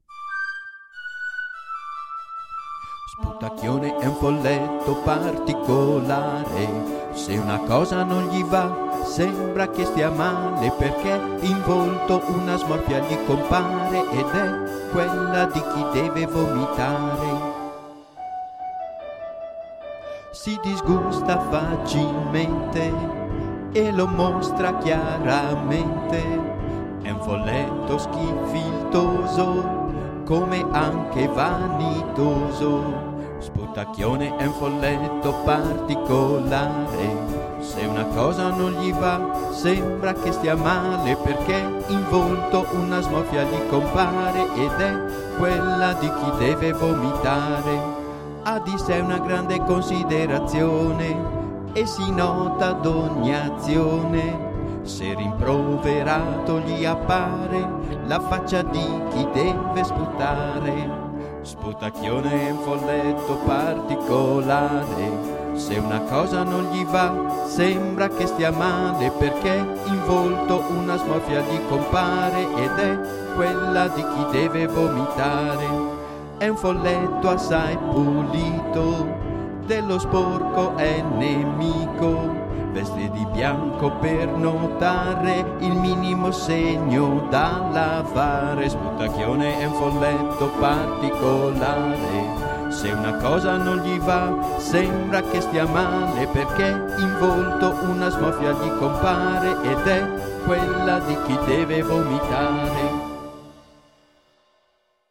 Canzoni dedicate ai sei Folletti scritte musicate e cantate